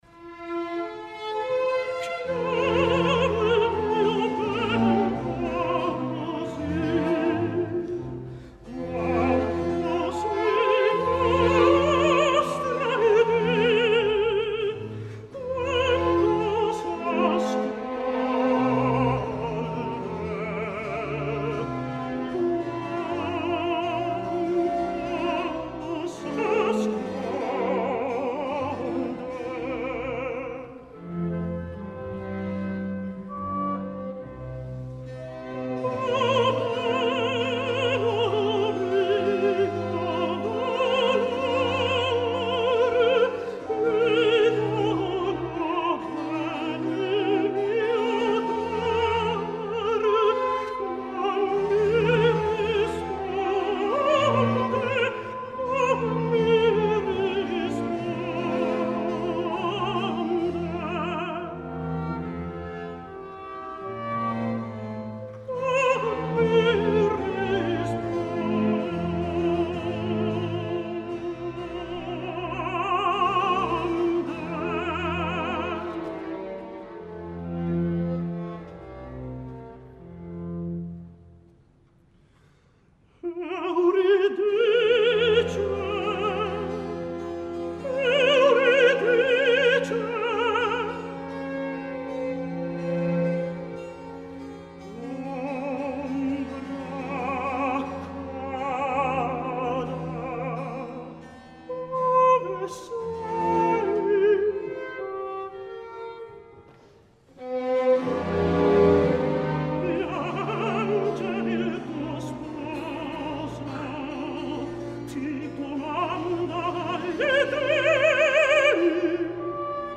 contratenor